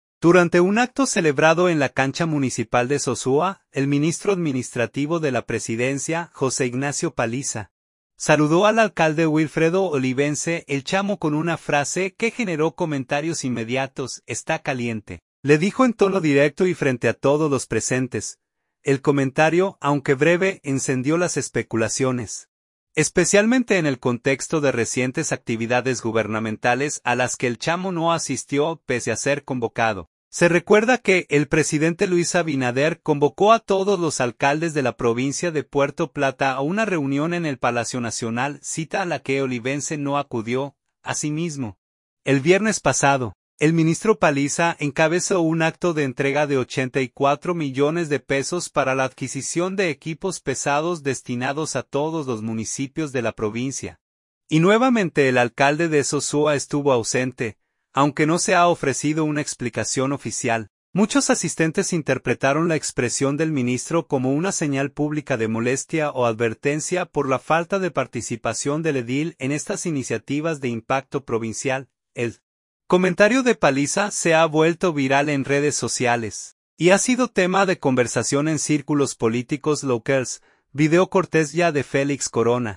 Sosúa, Puerto Plata.– Durante un acto celebrado en la cancha municipal de Sosúa, el ministro administrativo de la Presidencia, José Ignacio Paliza, saludó al alcalde Wilfredo Olivense (El Chamo) con una frase que generó comentarios inmediatos: “Está caliente”, le dijo en tono directo y frente a todos los presentes.